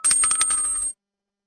Shell Casing Drop
A brass shell casing bouncing and settling on a hard concrete floor
shell-casing-drop.mp3